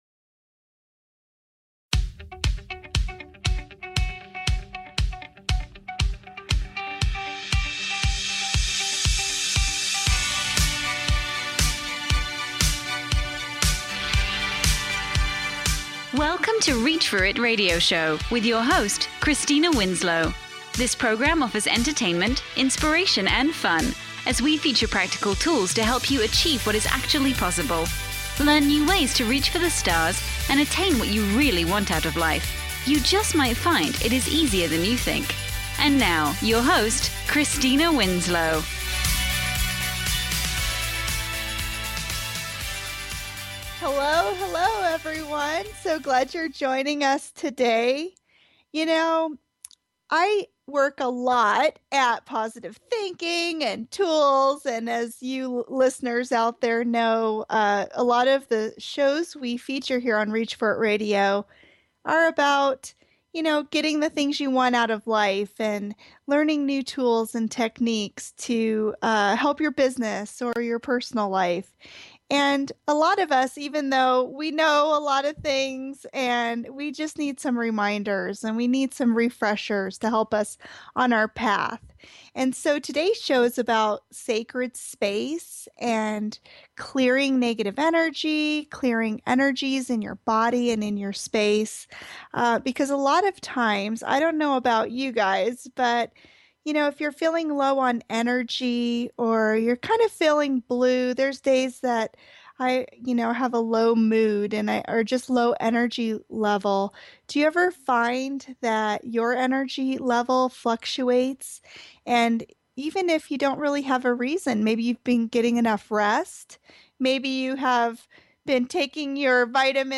Talk Show Episode, Audio Podcast, Reach For It Radio Show and Space Clearing with Sound on , show guests , about Sacred Space,Sound,Solfeggio,Earth Frequencies, categorized as Health & Lifestyle,Music,Ghosts,Self Help,Access Consciousness,Shamanism